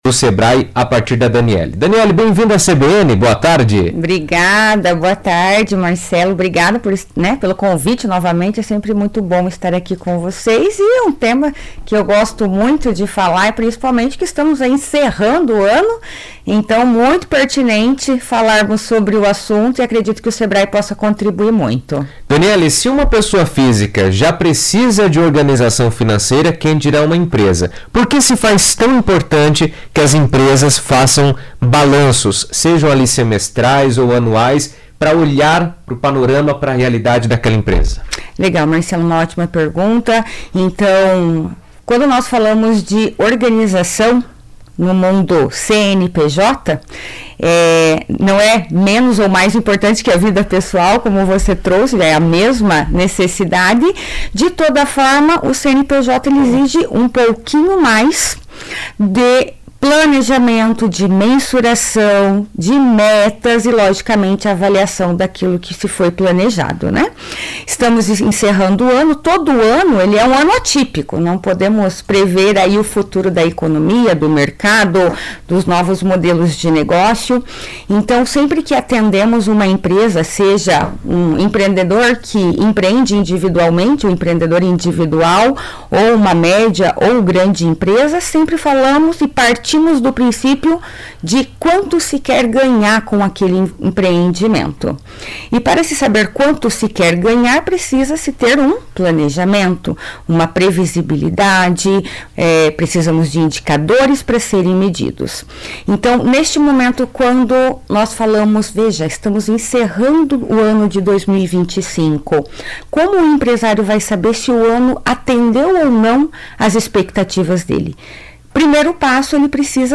Com a proximidade do fim do ano, é hora das empresas analisarem resultados, avaliarem desafios e traçarem novas metas para o próximo ciclo. Esse momento de balanço é essencial para ajustar estratégias, identificar oportunidades e planejar ações mais eficazes. Em entrevista à CBN